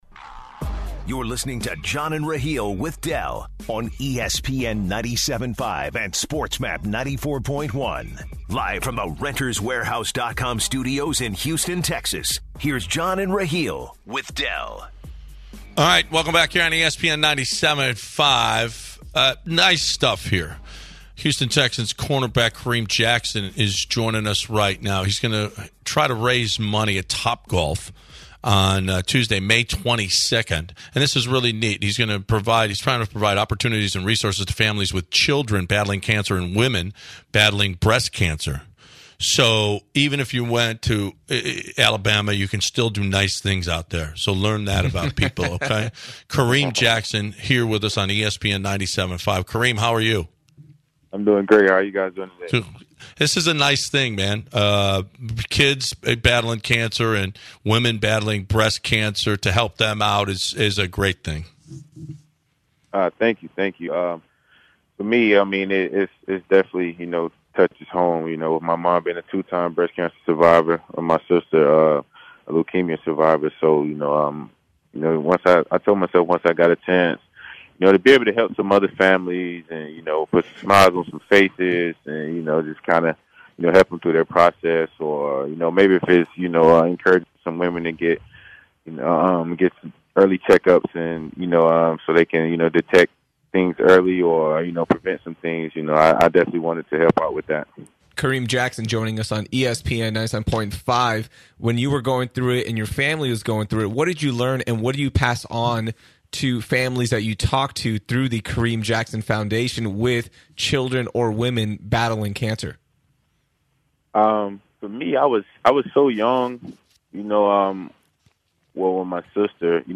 Texans’ corner Kareem Jackson interview